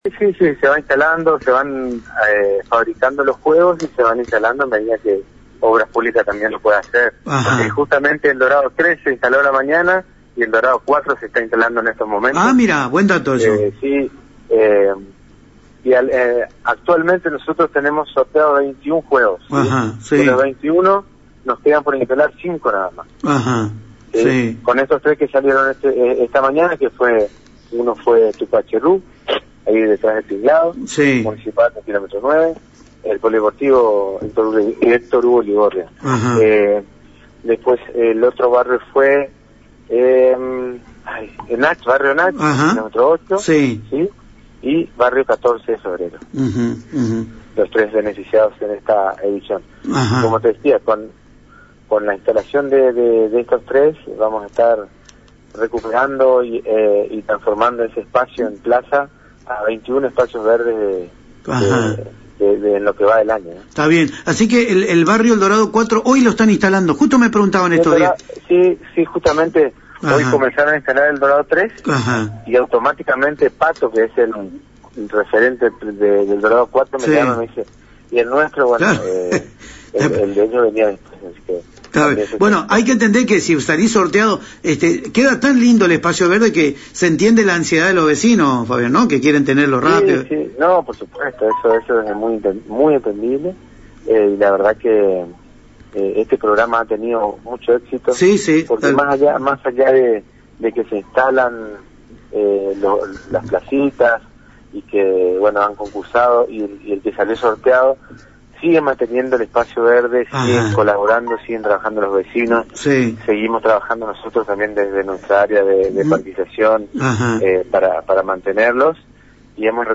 En diálogo con ANG y Radio Génesis